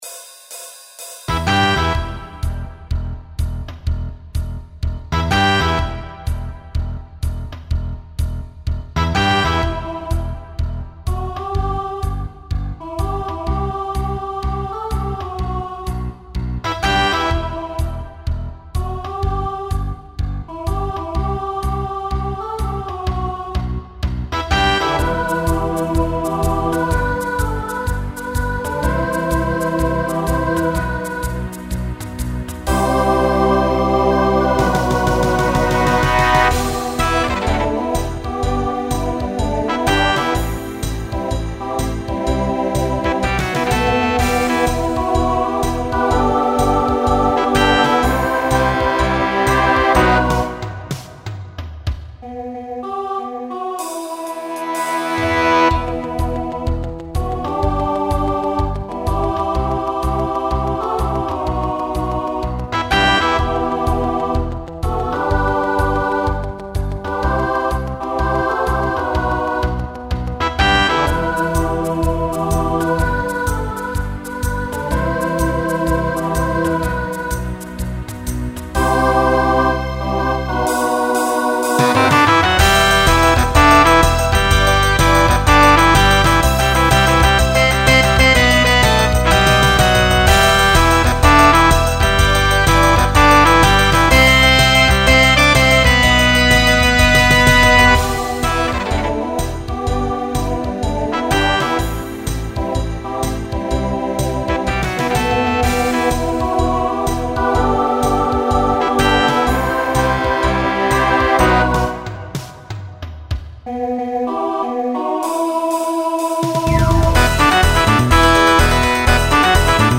Starts with a quartet to facilitate a costume change.
Genre Rock , Swing/Jazz
Transition Voicing SATB